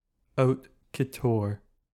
Haute couture (/ˌt kˈtjʊər/
En-us-haute-couture.ogg.mp3